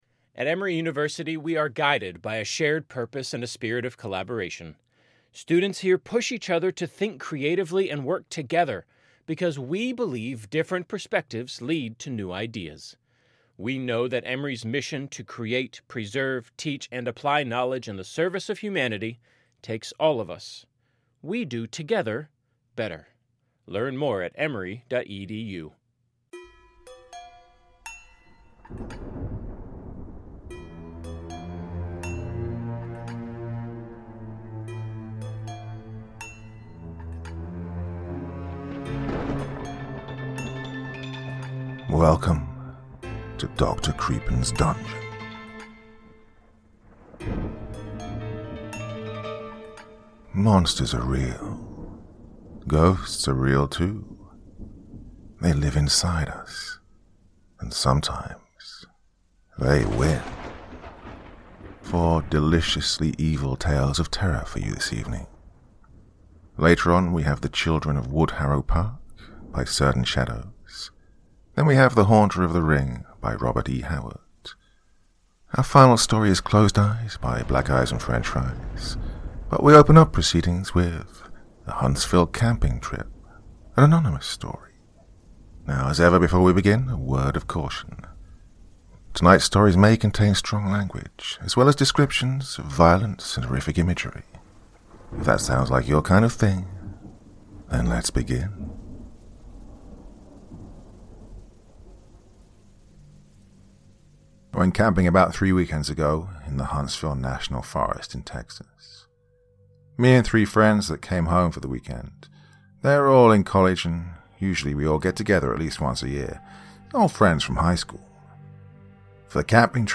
Episode 36: Ghosts and Monsters Horror Stories